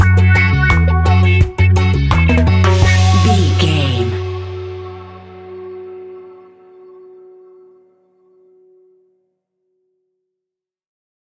Classic reggae music with that skank bounce reggae feeling.
Aeolian/Minor
F#
laid back
chilled
off beat
drums
skank guitar
hammond organ
percussion
horns